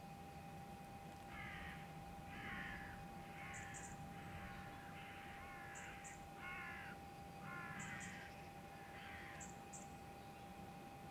Corvo pequeno
Corvus corone
Canto